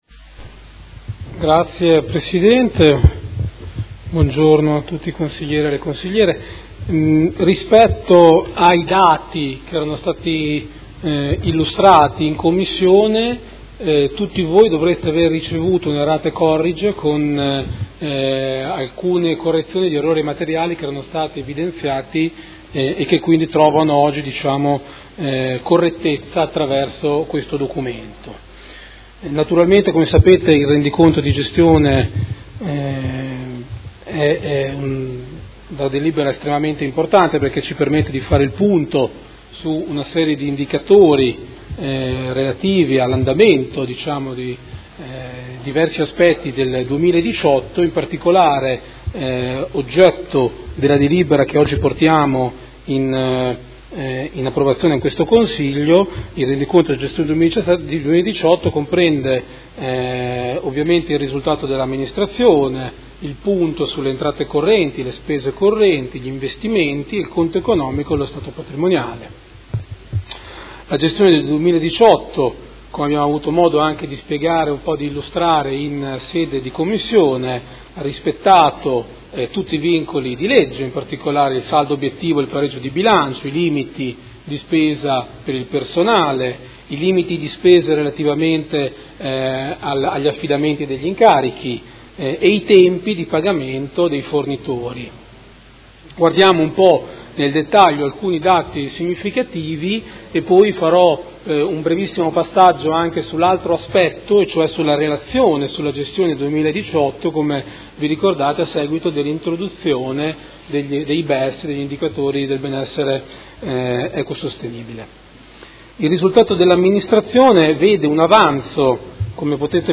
Seduta del 29/04/2019. Proposta di deliberazione: Rendiconto della Gestione del Comune di Modena per l’Esercizio 2018 - Approvazione